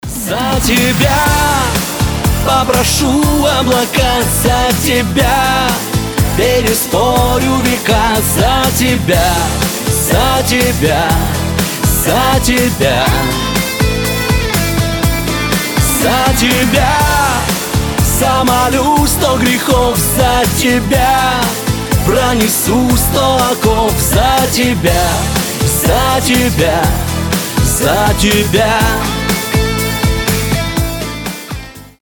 • Качество: 320, Stereo
душевные
русский шансон